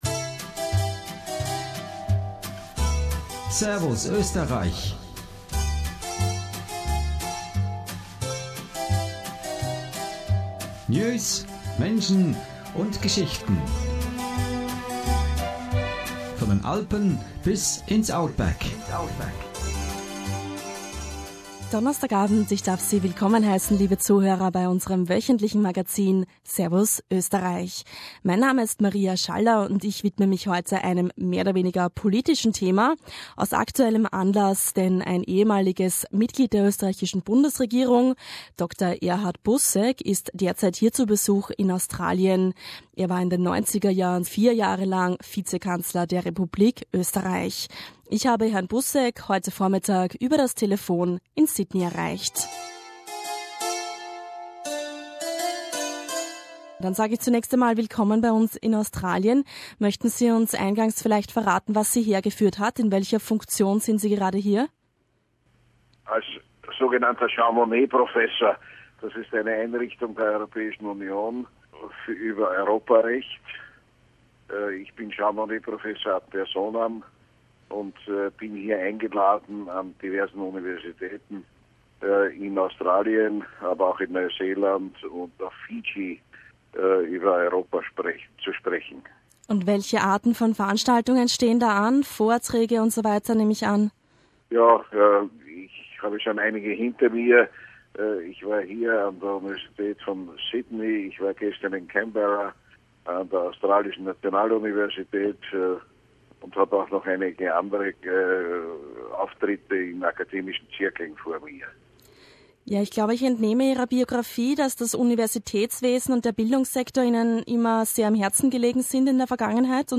During the 1990s, Busek served as vice-chancellor, as well as minister for education and science. In this interview, he also talks about the shift to the right which currently occurs in Austria.